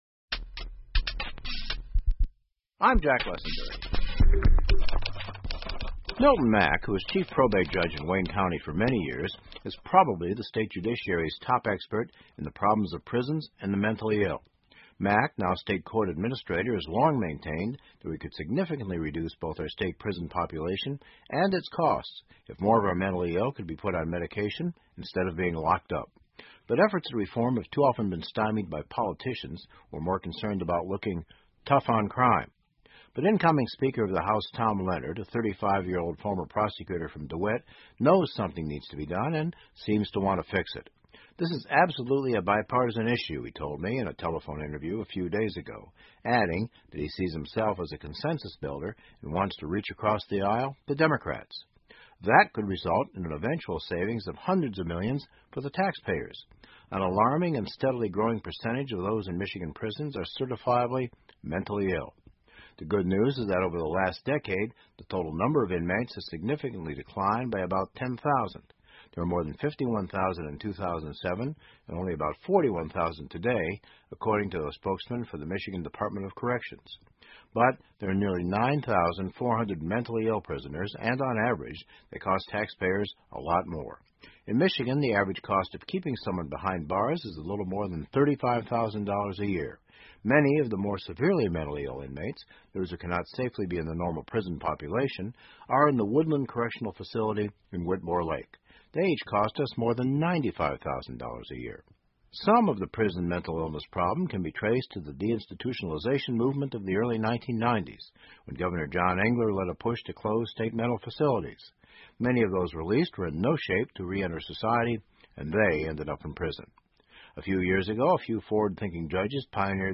密歇根新闻广播 治疗囚犯精神疾病的一线曙光 听力文件下载—在线英语听力室